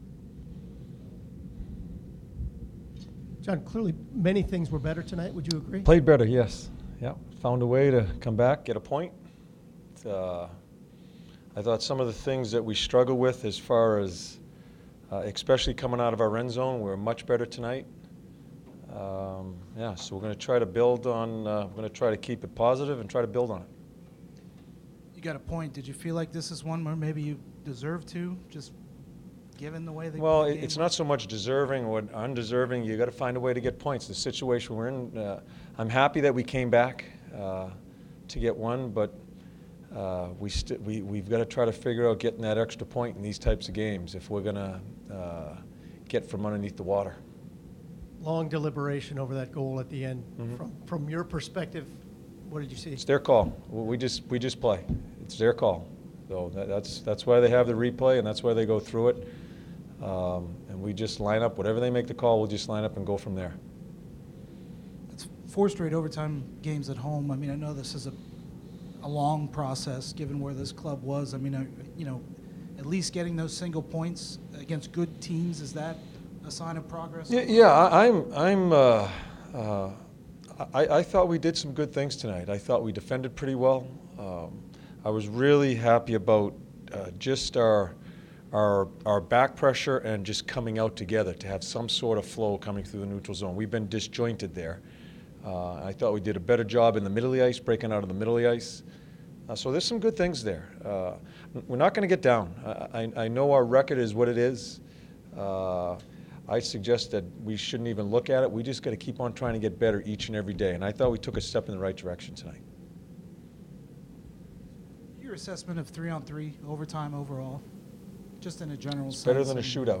Head Coach John Tortorella addresses media after loss to New York Islanders 3-2